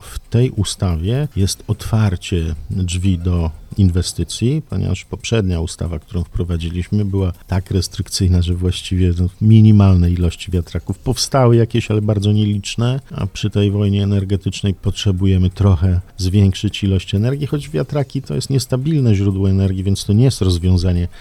wyjaśnia poseł PiS Marek Suski, dodając, że nie będzie się stawiać ludziom wiatraków w ogródkach: